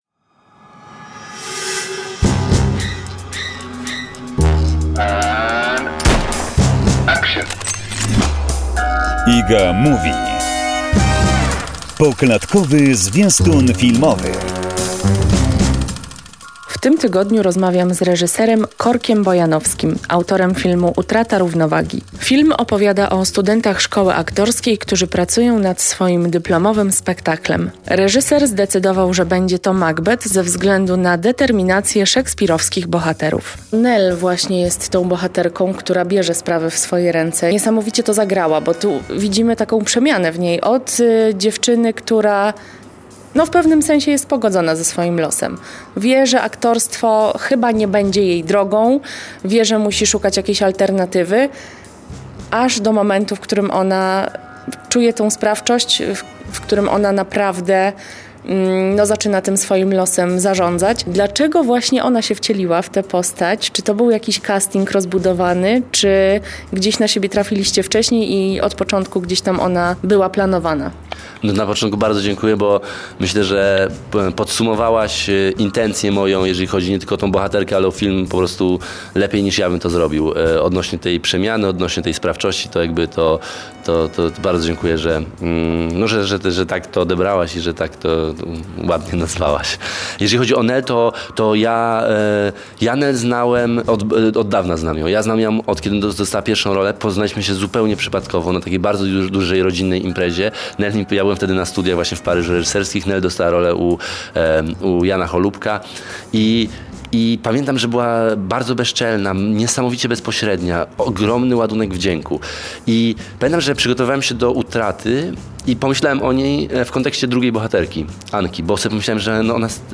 Kolejna odsłona rozmowy z Korkiem Bojanowskim, twórcą filmu „Utrata równowagi”.